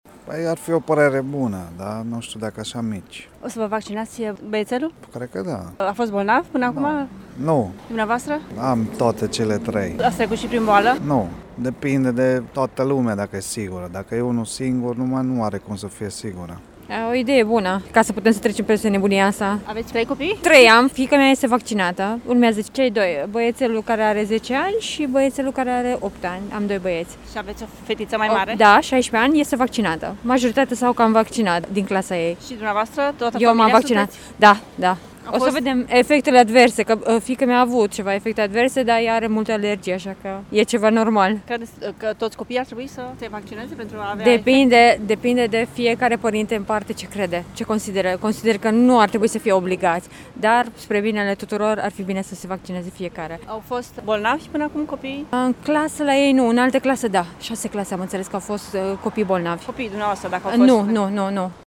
La Târgu Mureș, vaccinarea copiilor este așteptată în special de către părinții, care la rândul lor sunt imunizați: